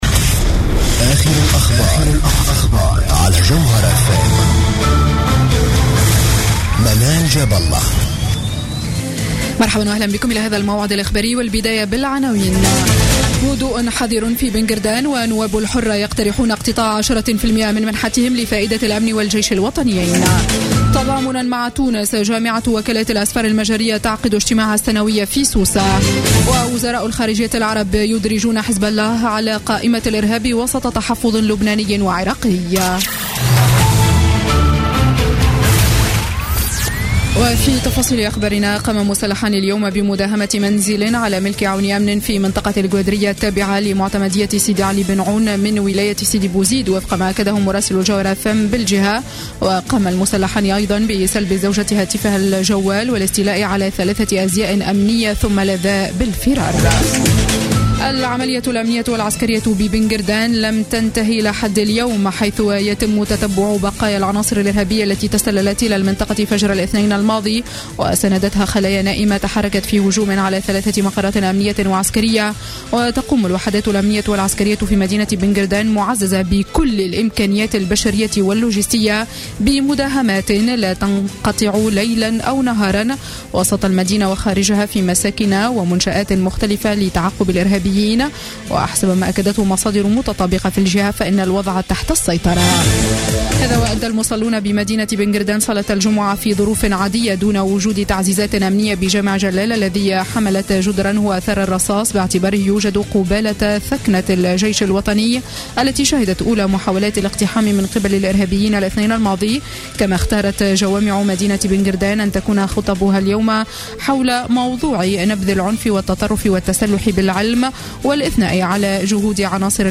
نشرة أخبار السابعة مساء ليوم الجمعة 11 مارس 2016